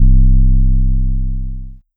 Globe 808.wav